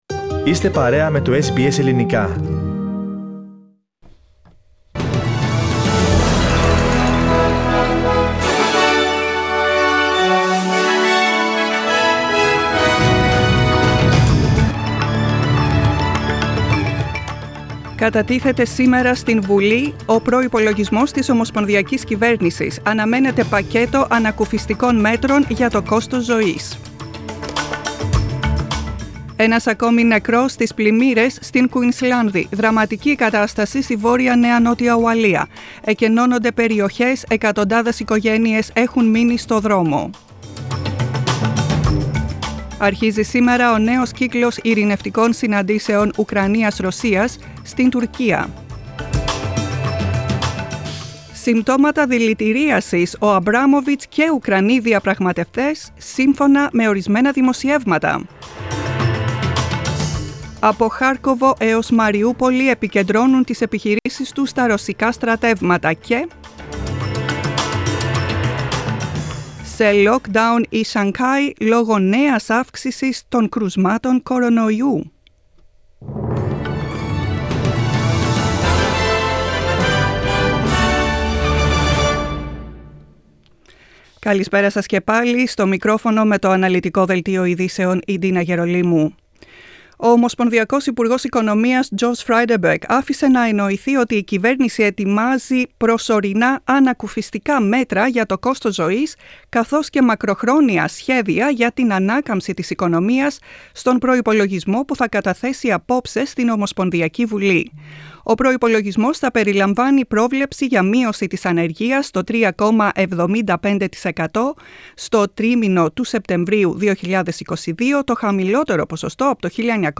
Δελτίο ειδήσεων, 29.03.22